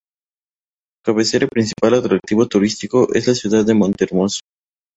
ca‧be‧ce‧ra
/kabeˈθeɾa/